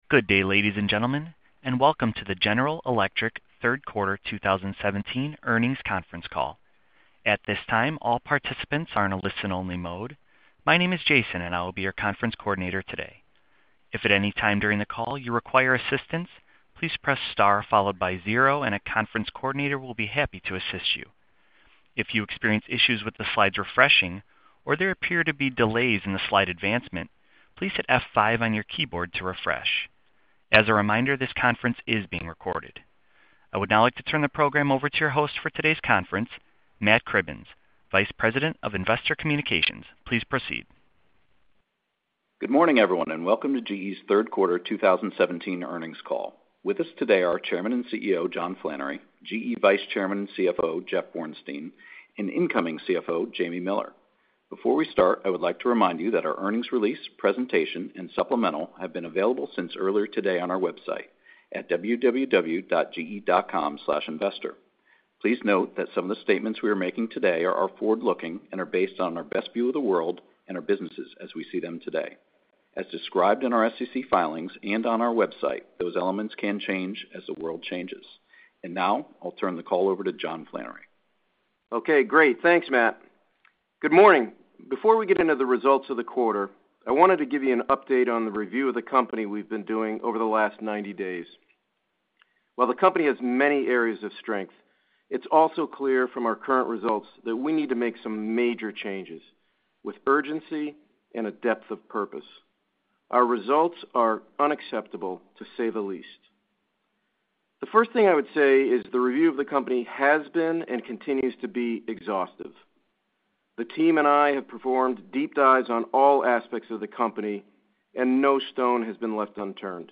GE 3rd Quarter 2017 Earnings Webcast | General Electric